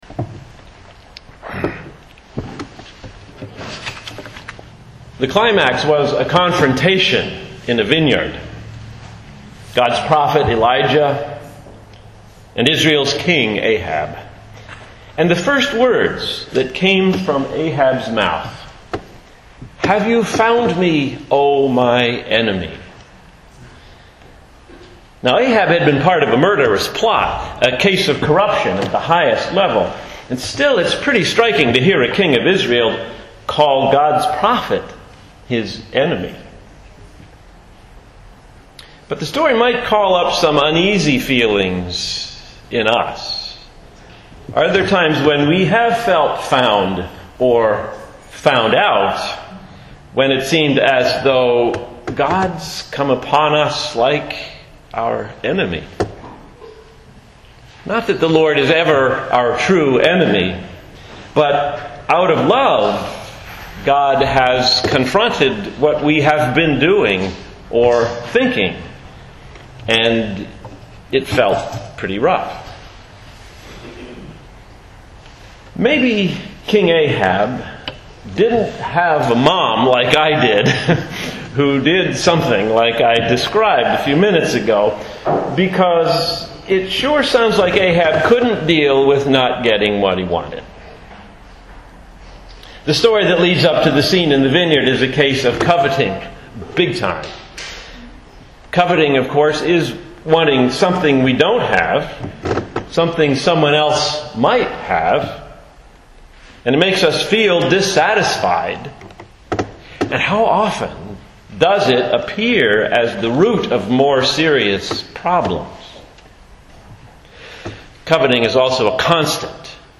Two Vintage Sermons